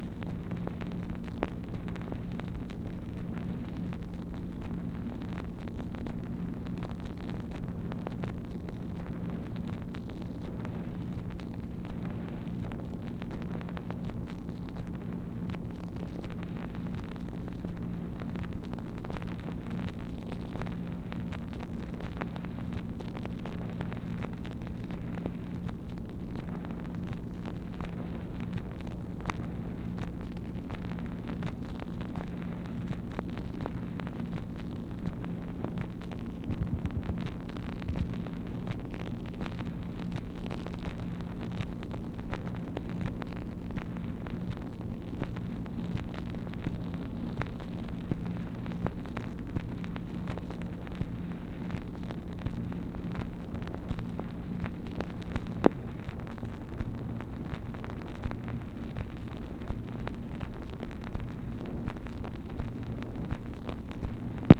MACHINE NOISE, April 8, 1964
Secret White House Tapes | Lyndon B. Johnson Presidency